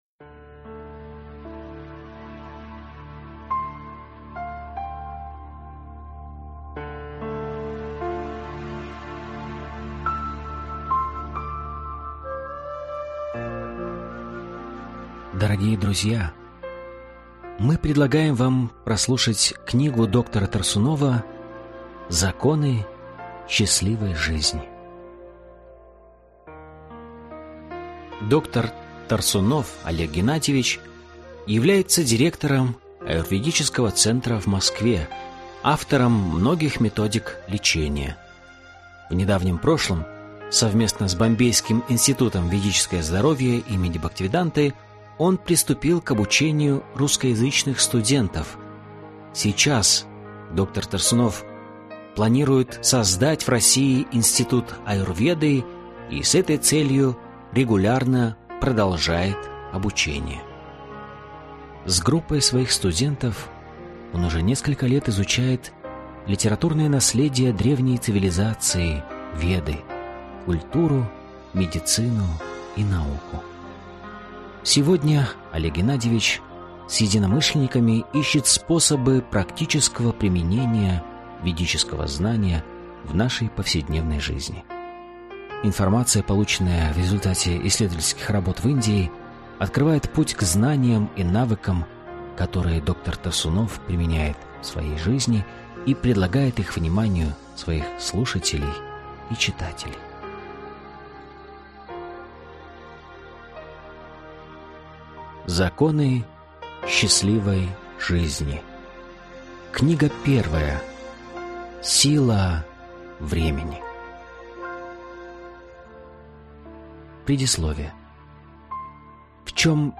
Аудиокнига Законы счастливой семейной жизни. Часть 1 | Библиотека аудиокниг